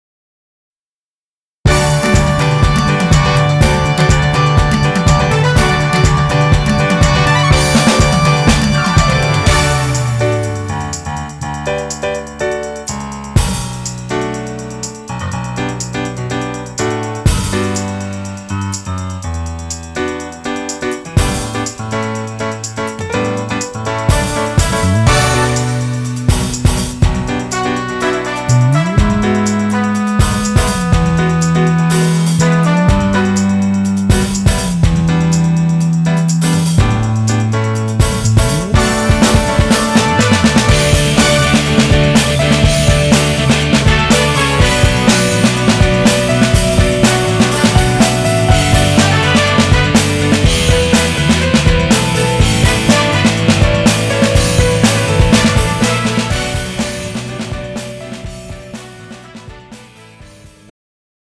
음정 멜로디MR
장르 축가 구분 Pro MR